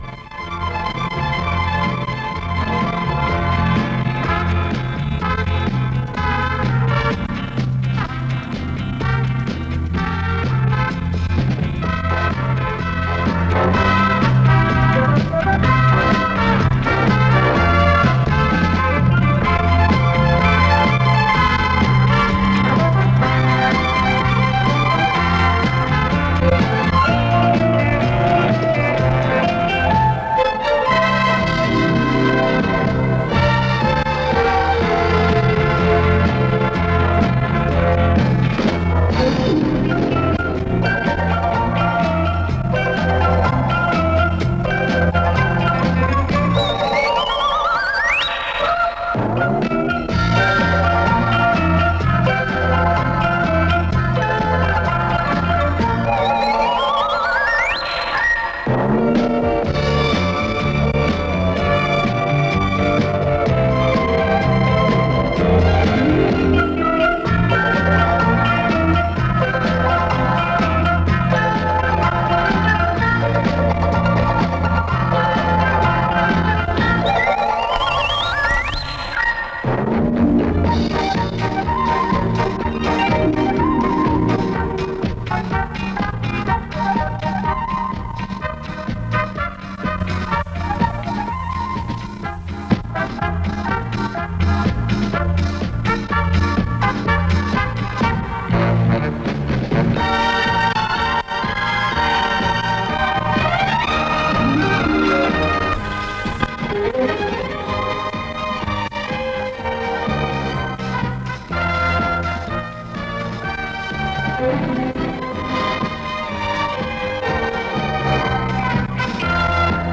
Помогите, пожалуйста, определить оркестры и исполняемые пьесы.
staraya-lenta-20--sound-2-ork.-gdr.mp3